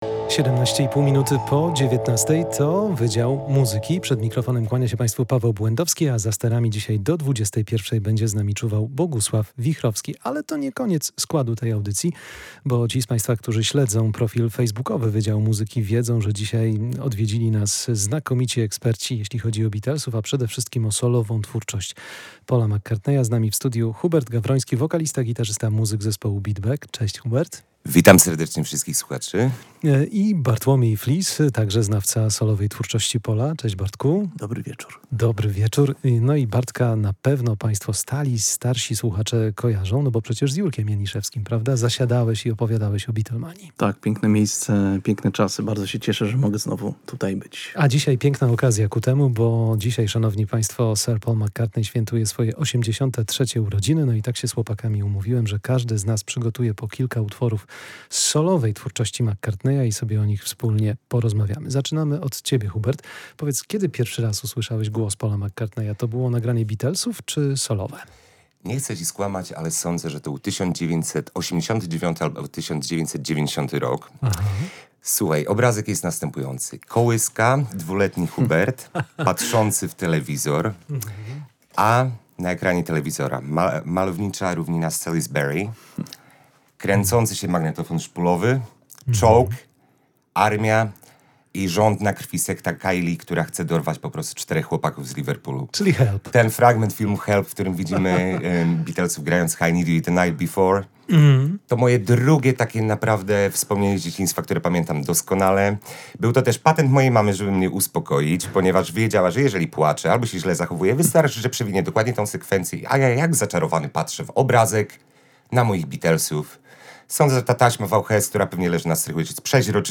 Z tej okazji studio odwiedzili znakomici eksperci